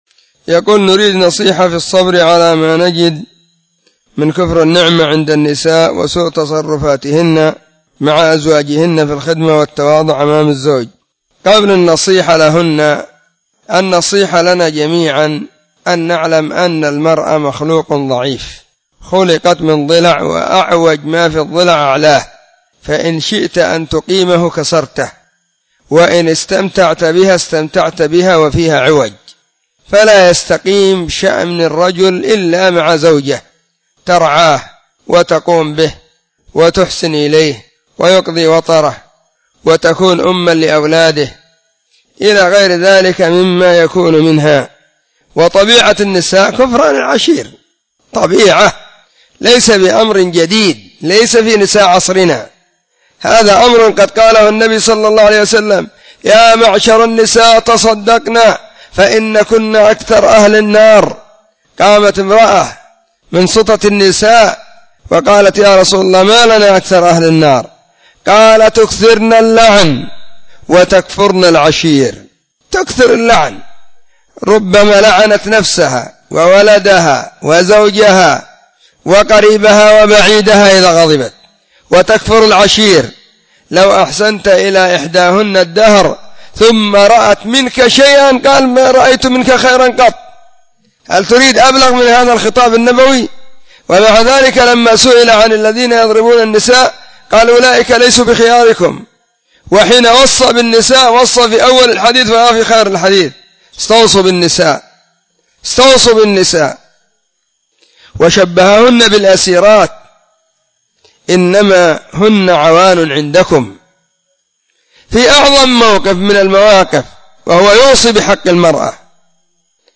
🔸🔹 سلسلة الفتاوى الصوتية المفردة 🔸🔹